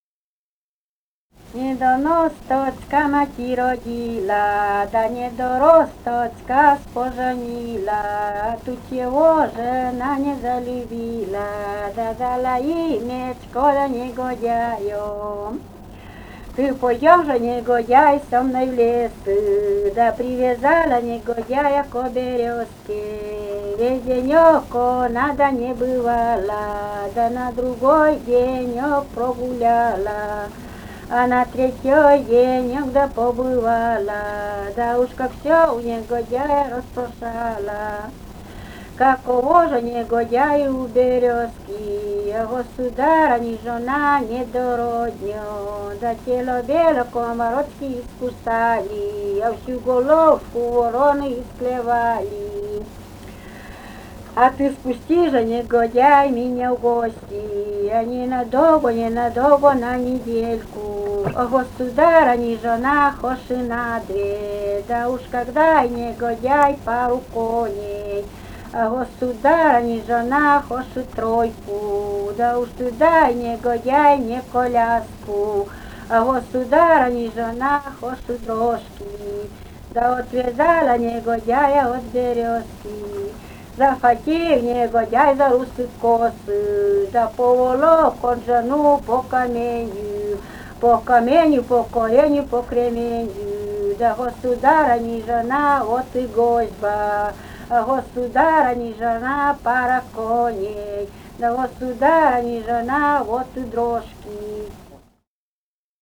Живые голоса прошлого 028.«Недоносточка мати родила» (плясовая).